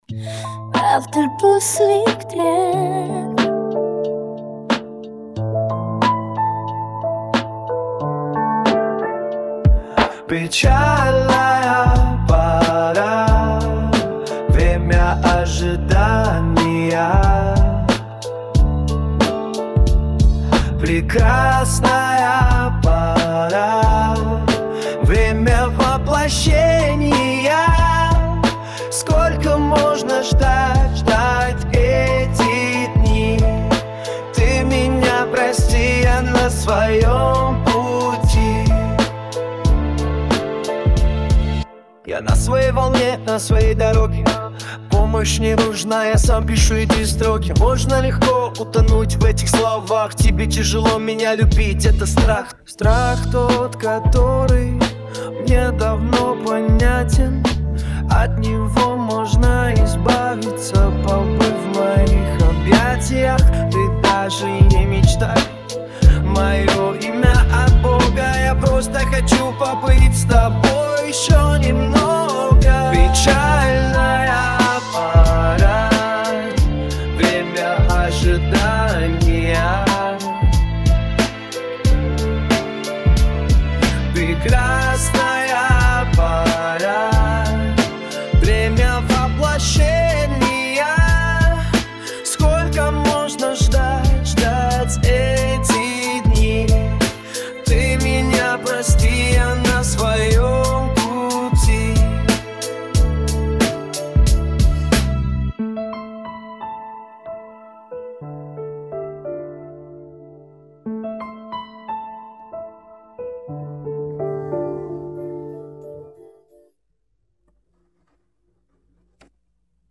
Пример музыки и голоса создан с помощью AI.